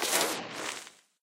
creeperdeath.ogg